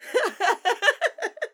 Vox
Laughter